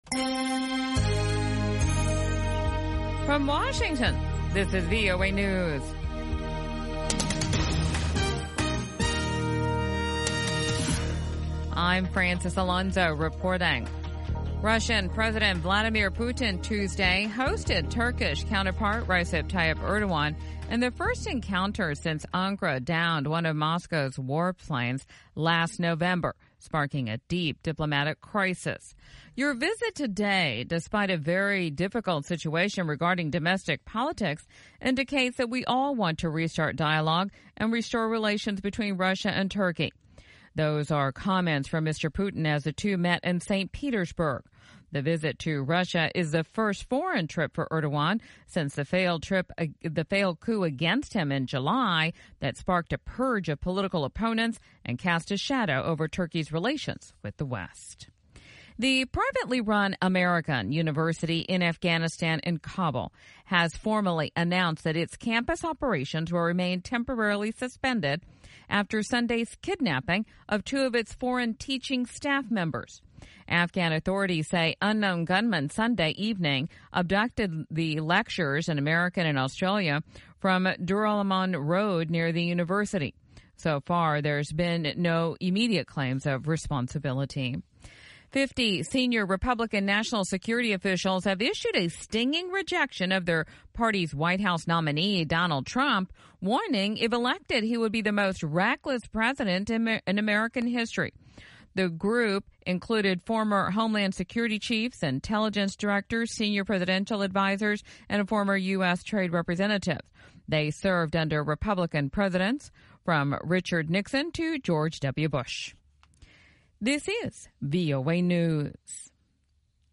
1300 UTC Newscast in English